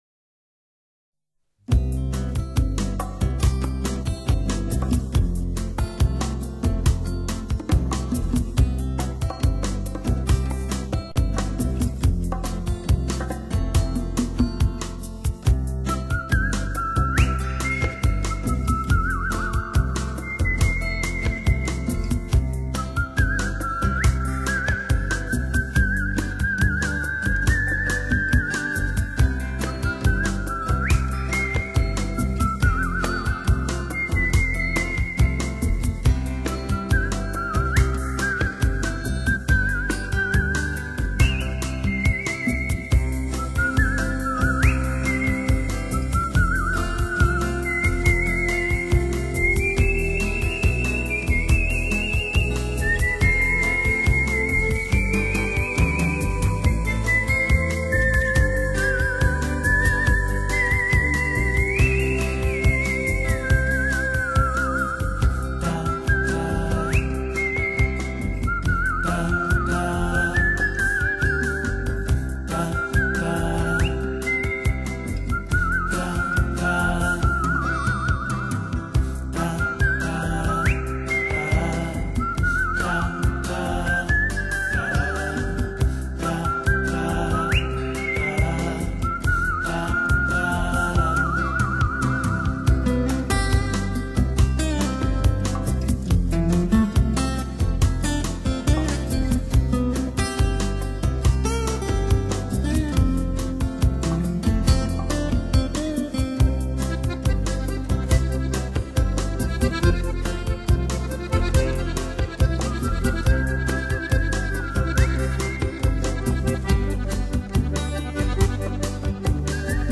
风格柔和中国新世纪音乐于一身
口哨